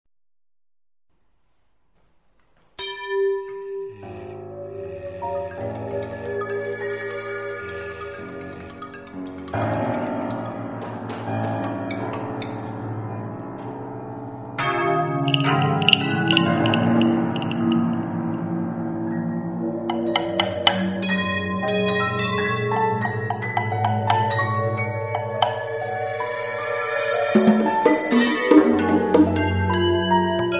Per strumenti vari (acustici e elettronici), voce femminile
con Raddoppio per percussioni e pianofore